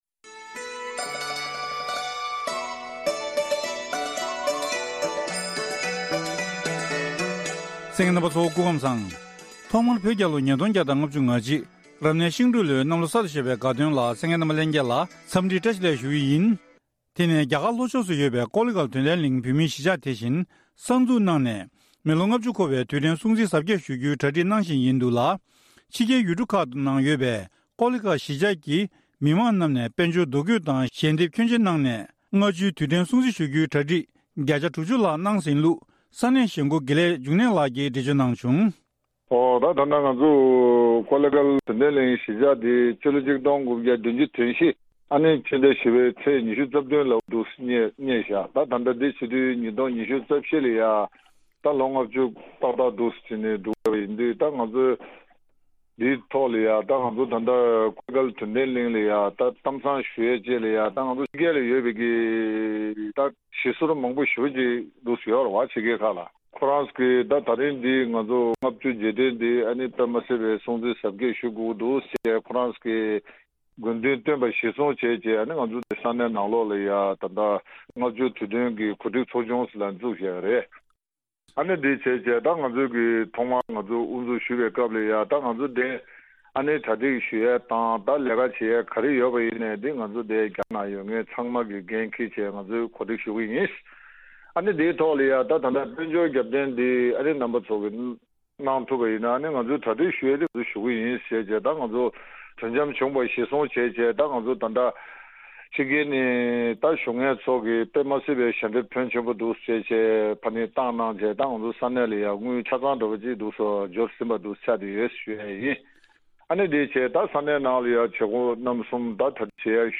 བཀའ་འདྲི་ཕྱོགས་སྒྲིགས་ཞུས་པ་ཞིག